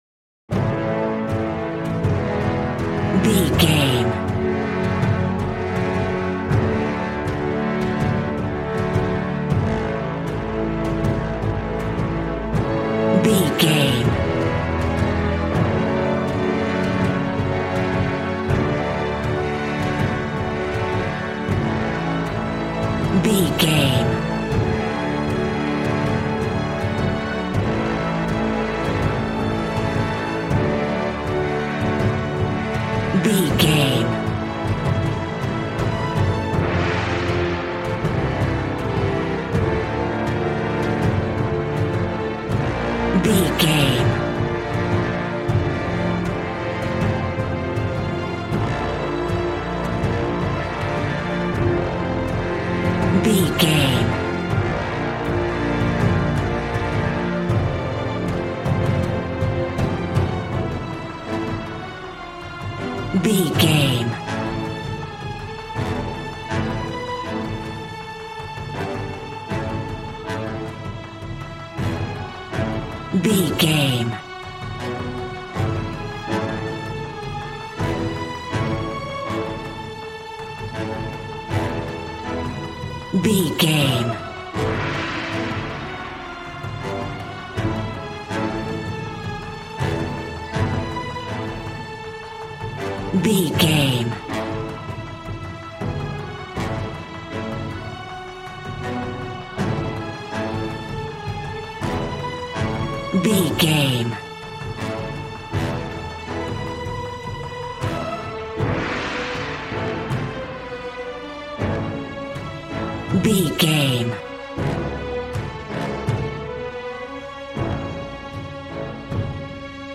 Action and Fantasy music for an epic dramatic world!
Ionian/Major
groovy
drums
bass guitar
electric guitar